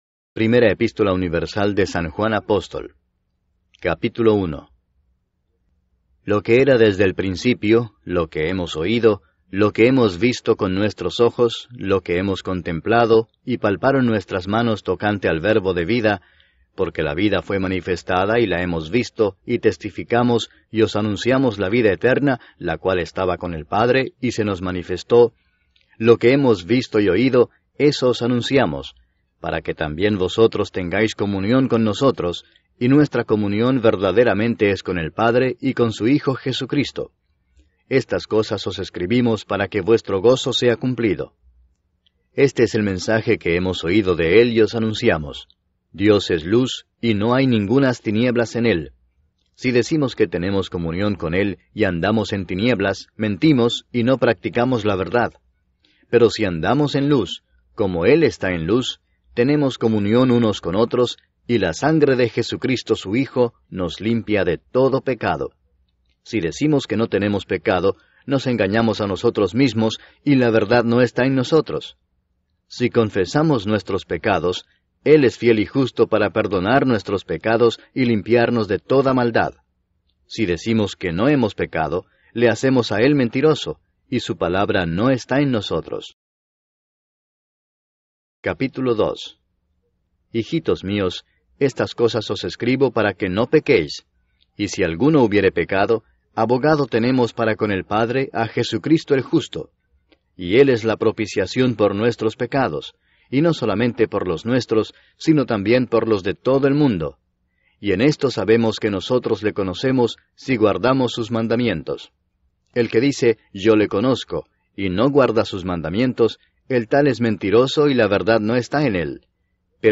El evangelio de Mateo completo narrado: Jesús frente a la religión y el Reino de Dios